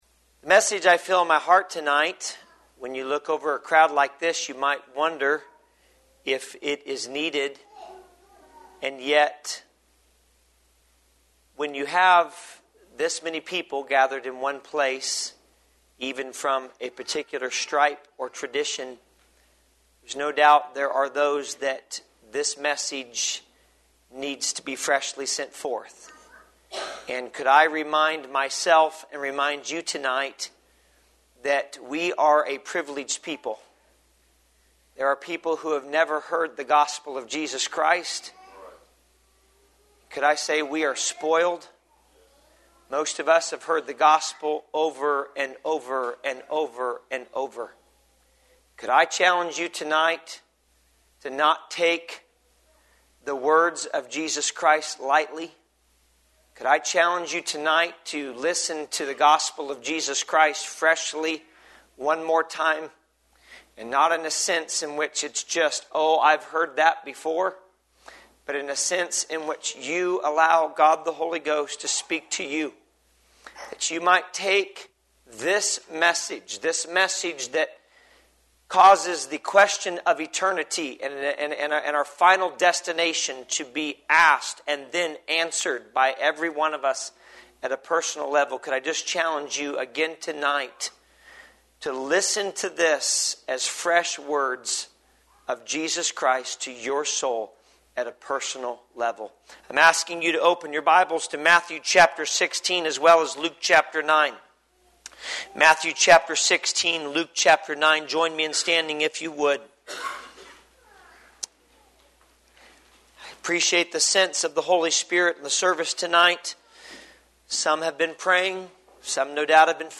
A sermon
Series: Spring Revival 2019